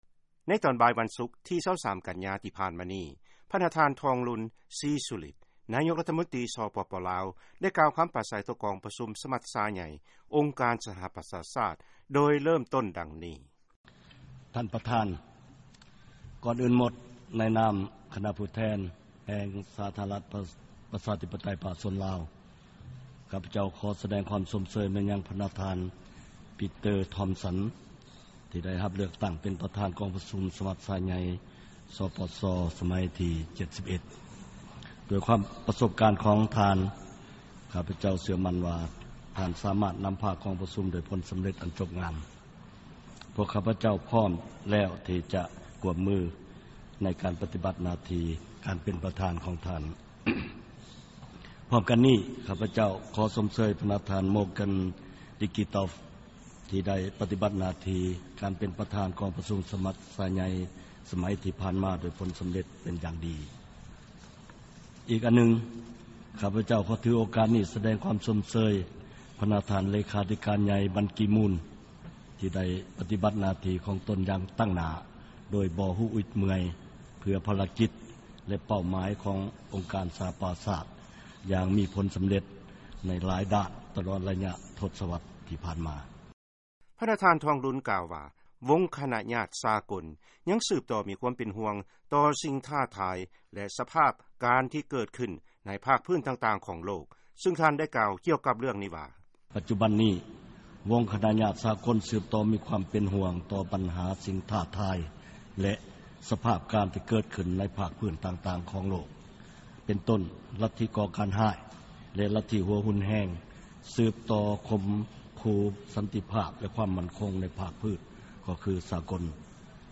ເຊີນຟັງຄຳປາໄສ ຂອງນາຍົກລັດຖະມົນຕີ ສປປ ລາວ ພະນະທ່ານທອງລຸນ ສີສຸລິດ ທີກອງປະຊຸມສະມັດຊາໃຫຍ່ສະຫະປະຊາຊາດ